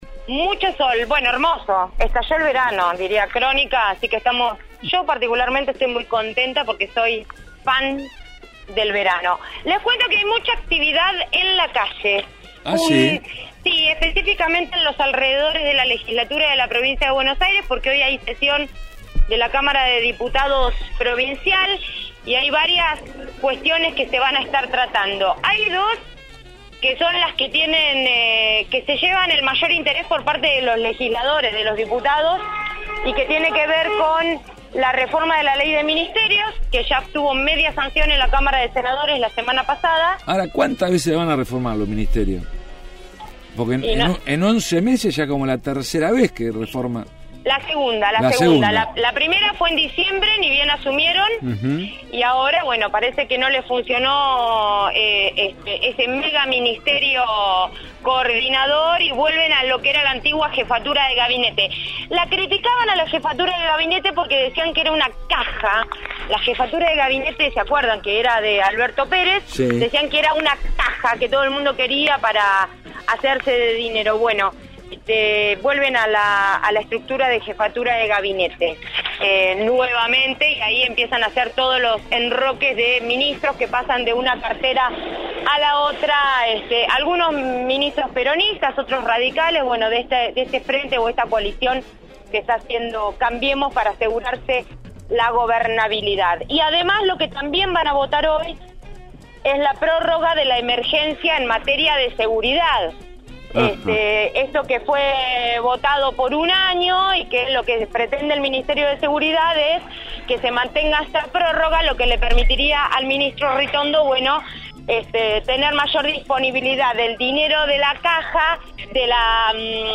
desde la Legislatura de la Provincia de Buenos Aires